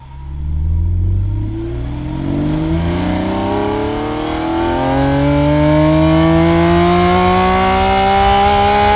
rr3-assets/files/.depot/audio/Vehicles/v12_04/v12_04_accel.wav
v12_04_accel.wav